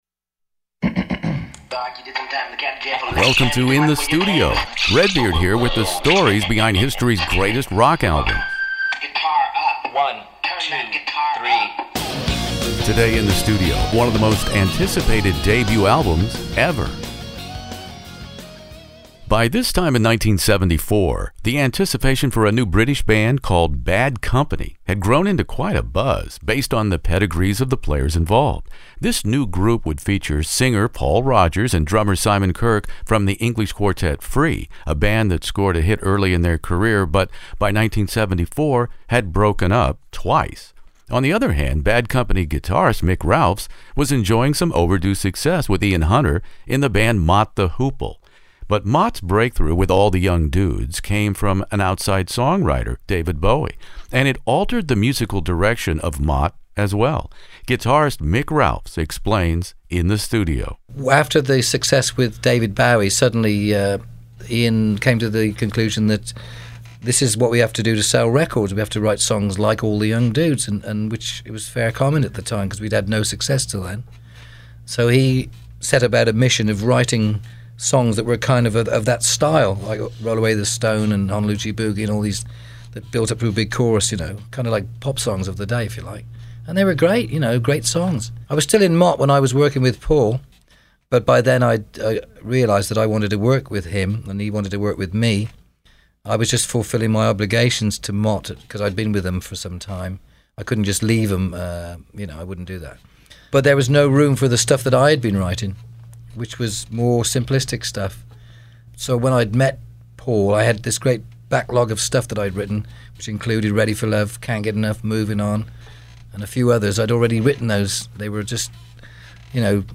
Bad Company "Bad Company" interview with Paul Rodgers, Mick Ralphs, Simon Kirke In the Studio